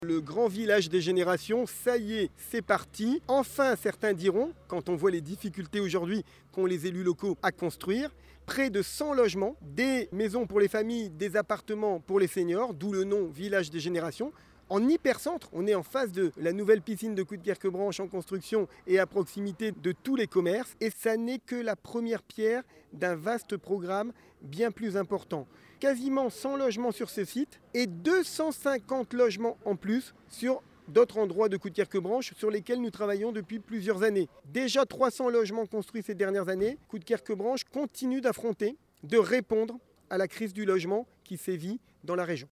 Le maire de Coudekerque-Branche David Bailleul s’exprime sur le sujet (DeltaFM du 18 juin 2025) :